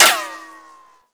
metalsolid1.wav